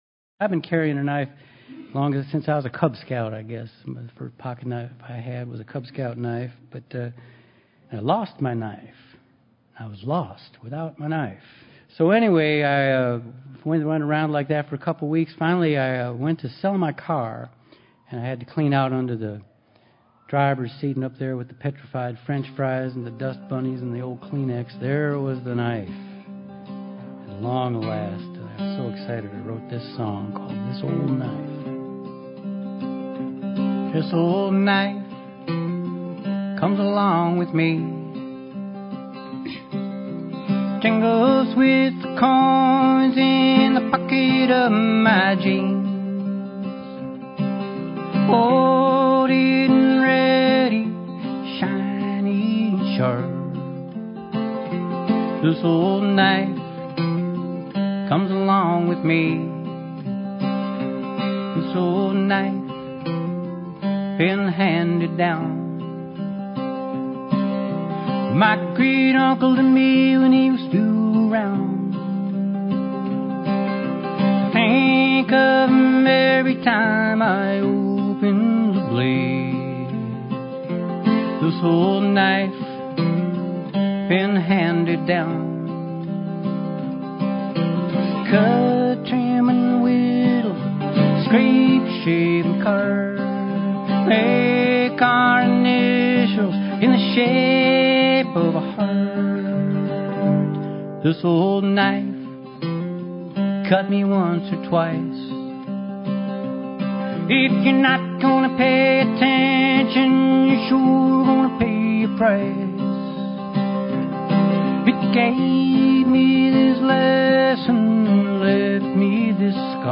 recorded live in front of an audience